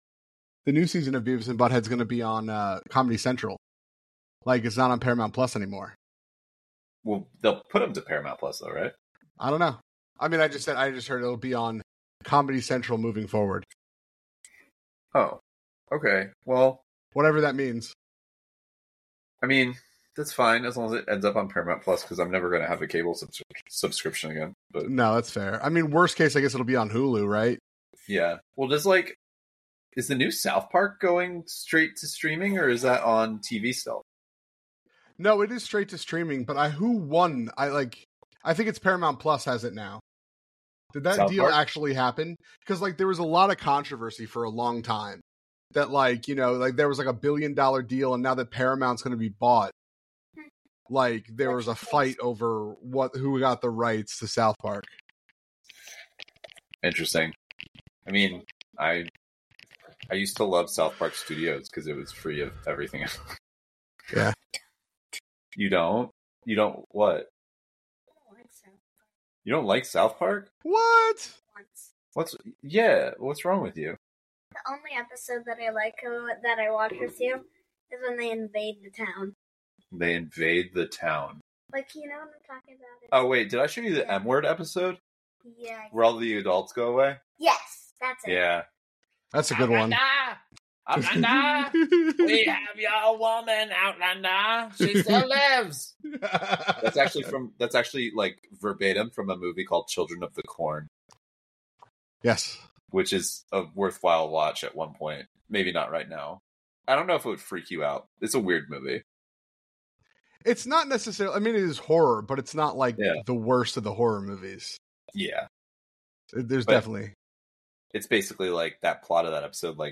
In this engaging conversation, the hosts discuss the return of Beavis and Butthead on Comedy Central, the implications of streaming rights for shows like South Park, and their favorite episodes from both the new and old series. They explore the character dynamics between Beavis and Butthead, share memorable quotes, and reflect on the show’s impact on comedy. The discussion also touches on the nostalgia associated with the series and the excitement for future content.